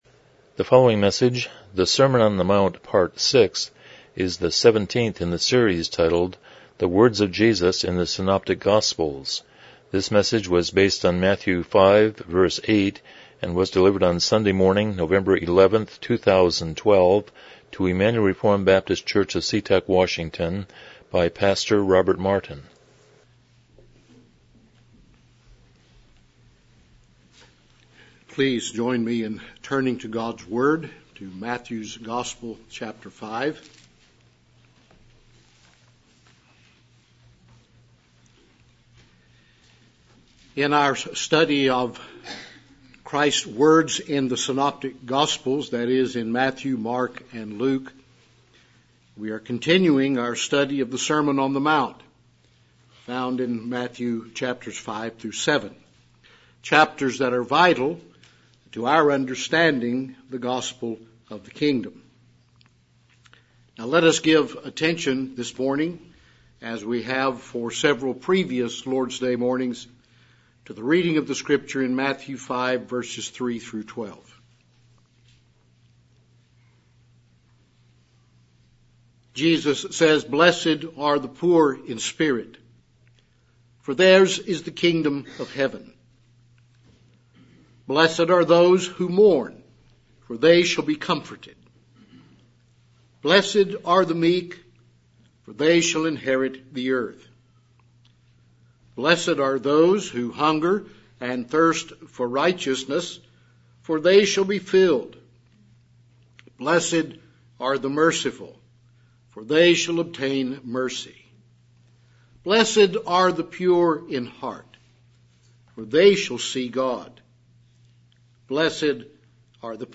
Passage: Matthew 5:8 Service Type: Morning Worship